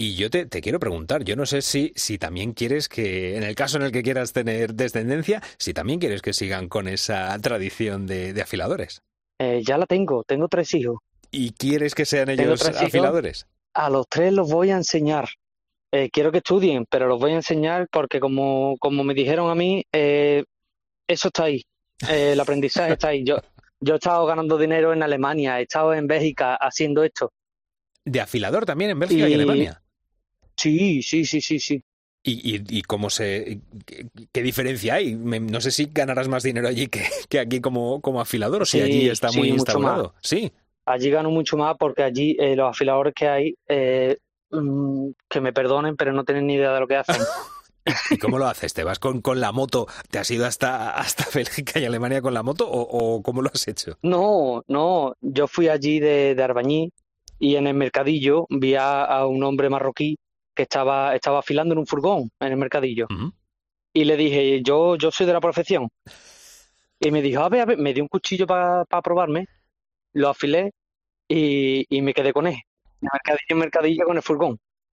Un afilador